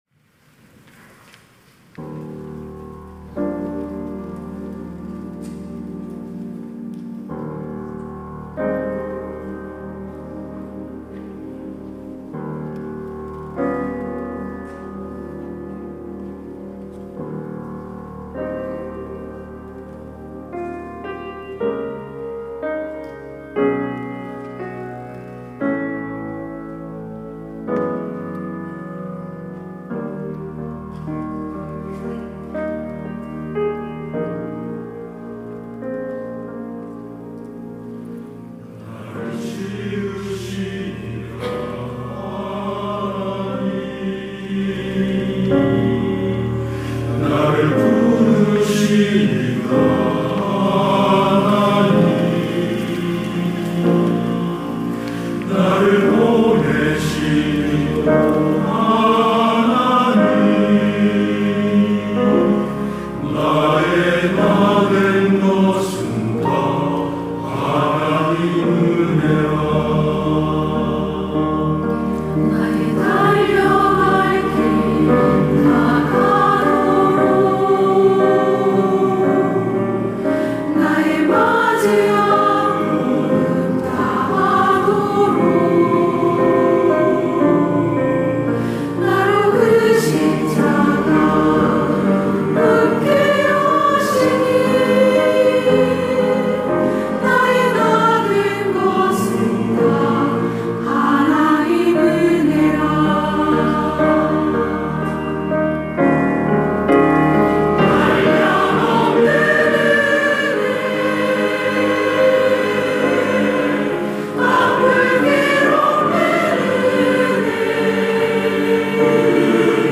시온(주일1부) - 하나님의 은혜
찬양대